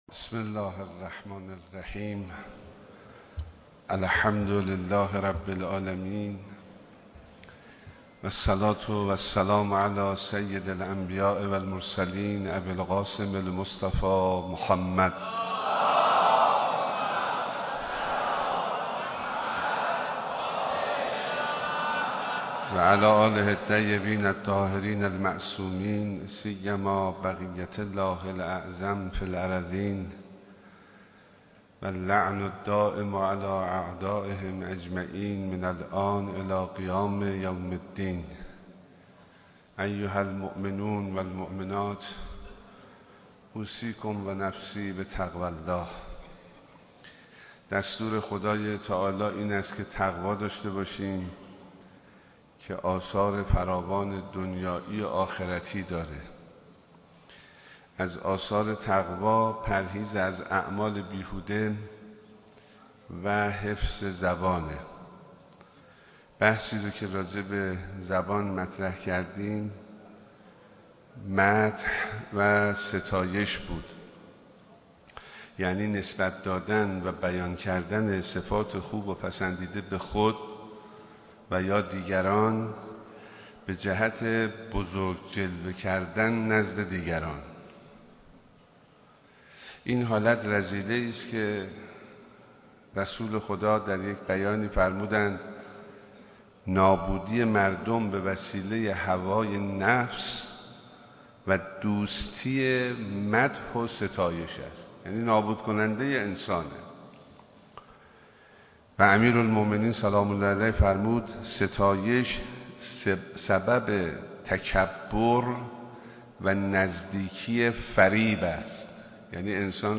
خطبه های نماز جمعه 96/10/22 | امام جمعه کرج